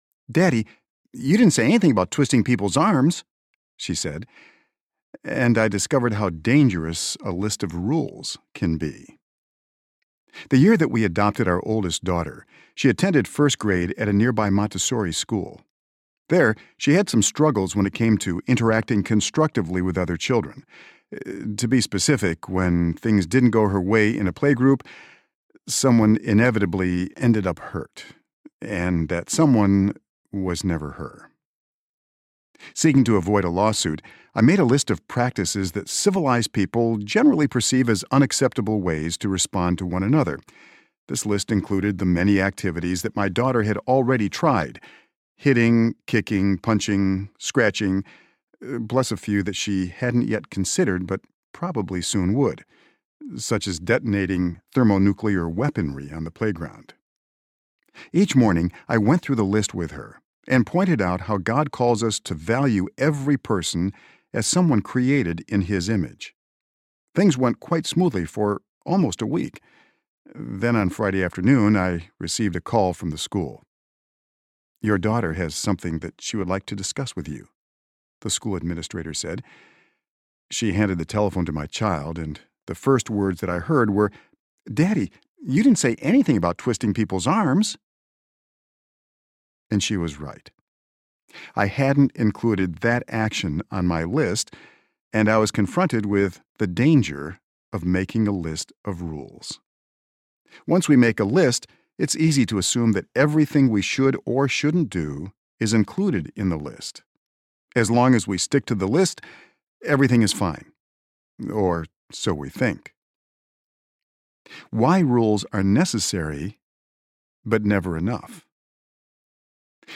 The Gospel & Parenting (Gospel for Life Series, Book #8) Audiobook
3.07 Hrs. – Unabridged